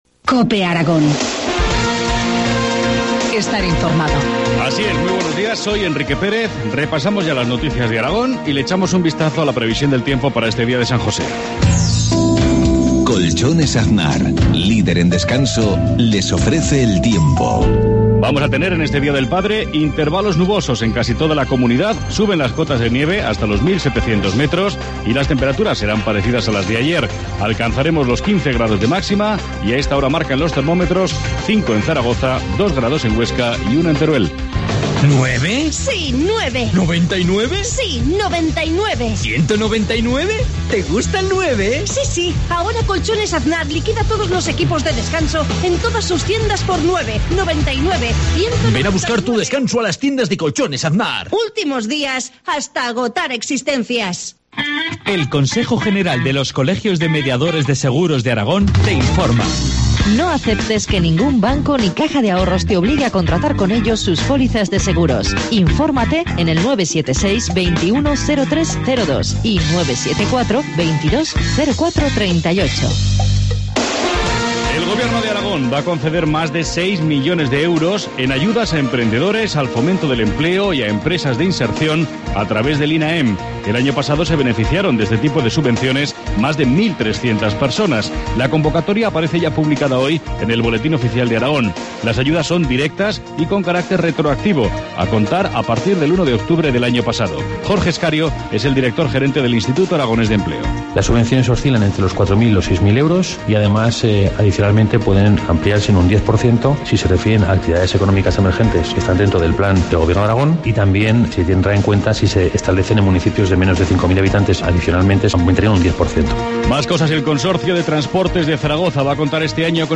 Informativo matinal, martes 19 de marzo, 7.53 horas